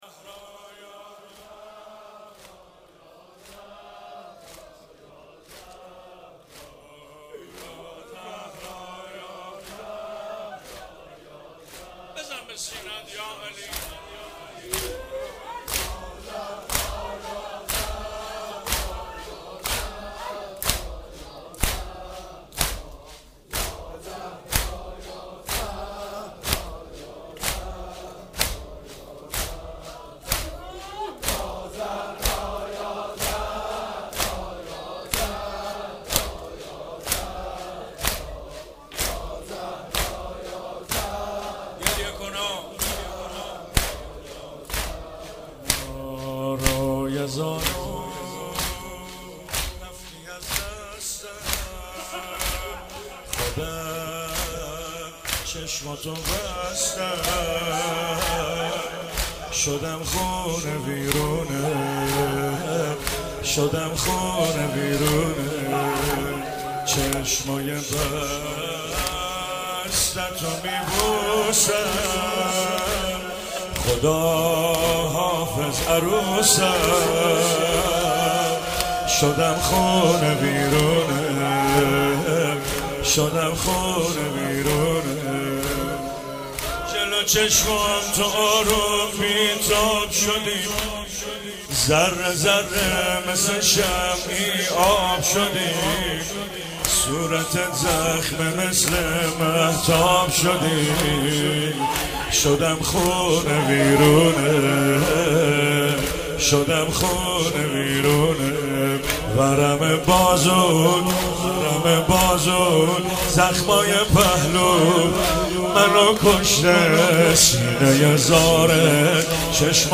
زمینه - روی زانو رفتی از دستم خودم چشماتو